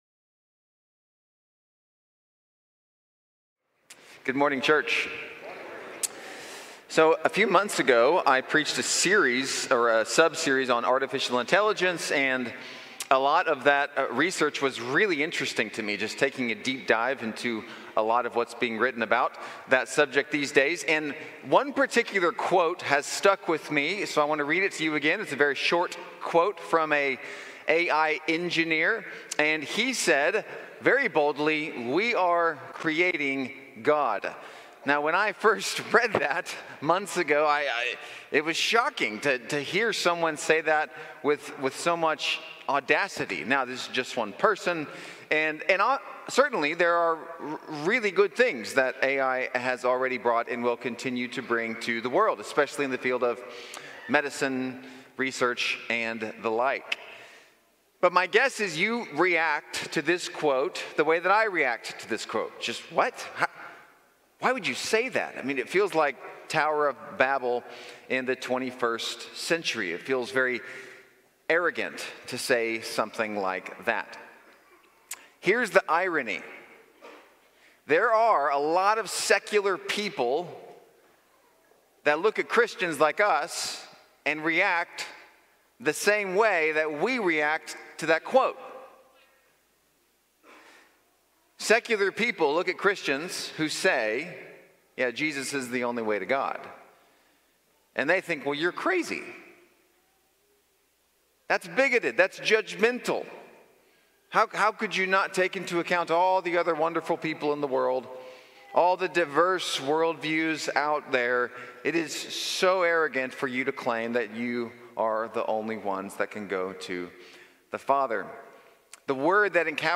Series: Believe in Me, Sunday Morning